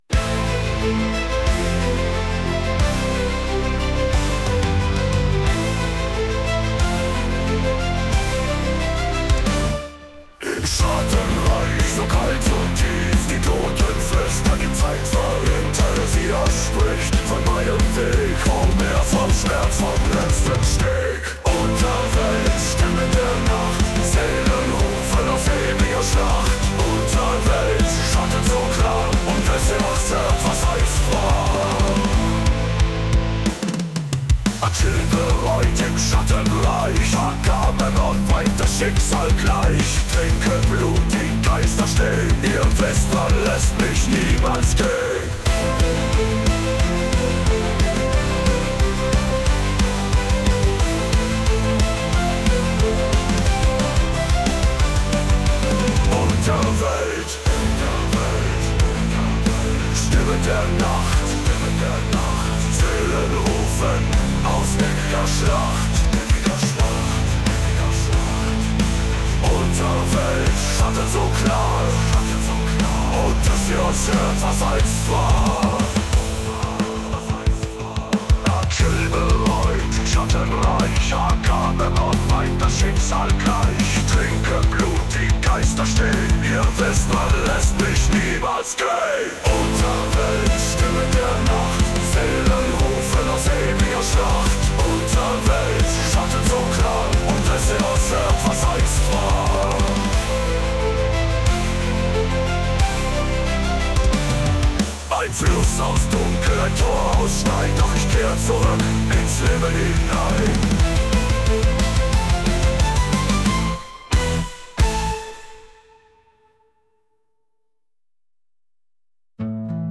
mit ChatGPT getextet und mit SUNO vertont.